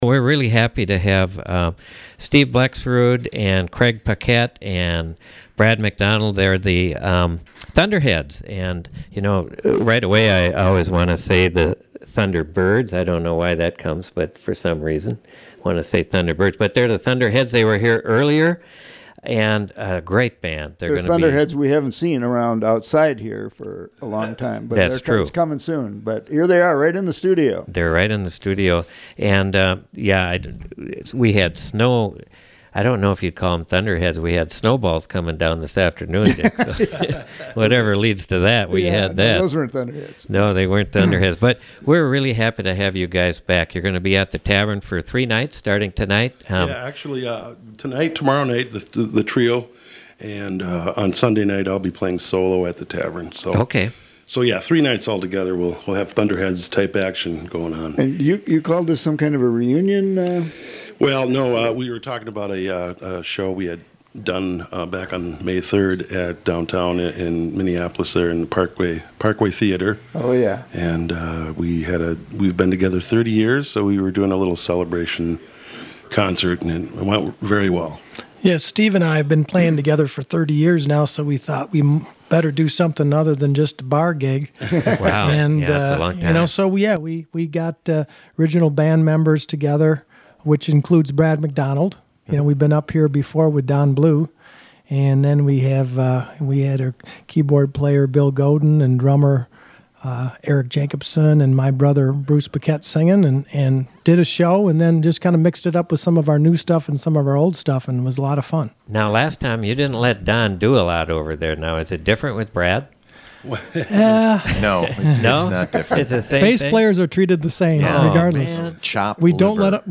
brought their great music to Studio A May 16.